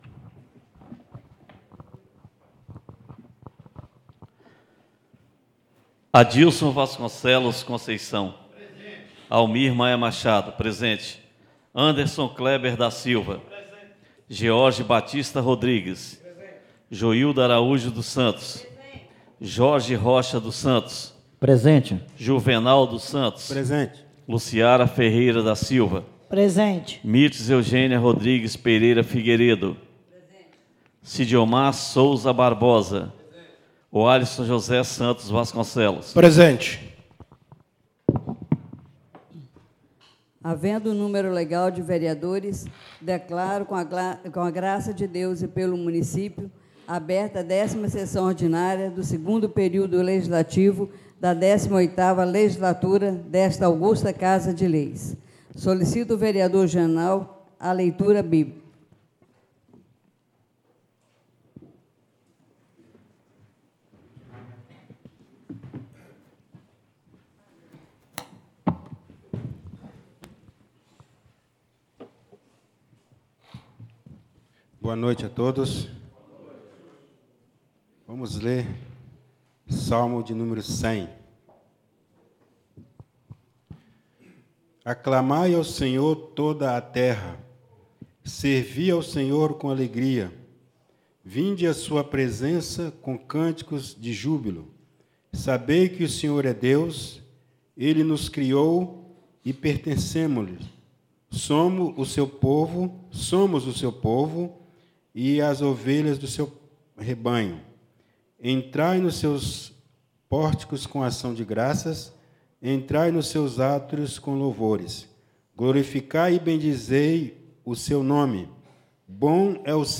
10ª SESSÃO ORDINÁRIA EM 02 DE AGOSTO DE 2018-SEDE